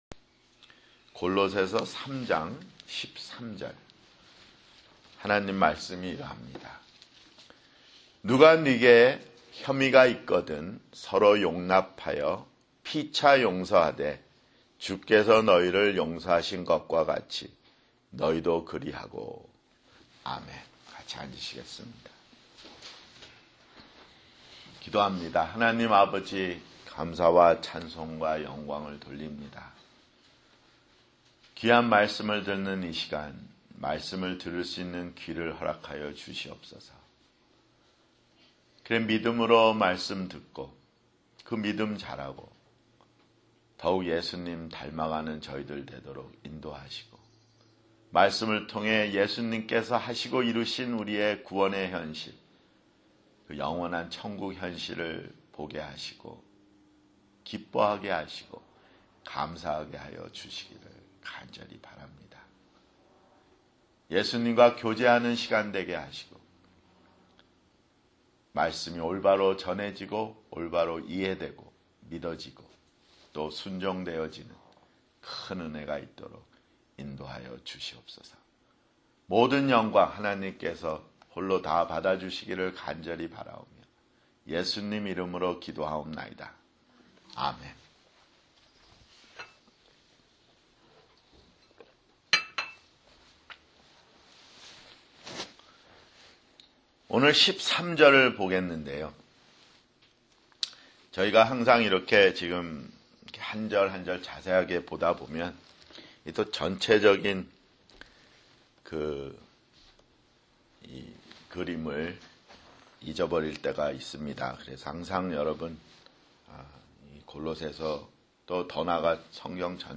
[주일설교] 골로새서 (67)